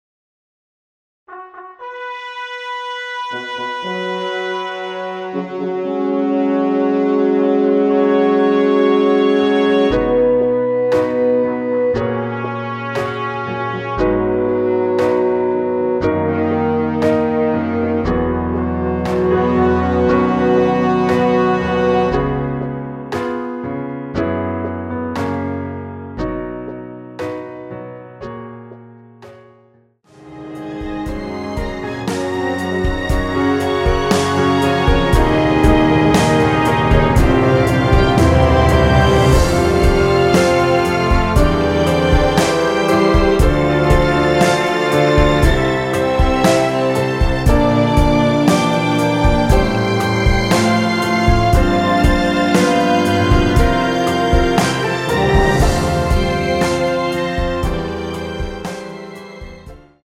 엔딩이 너무 길어 라이브에 사용하시기 좋게 짧게 편곡 하였습니다.(원키 미리듣기 참조)
앞부분30초, 뒷부분30초씩 편집해서 올려 드리고 있습니다.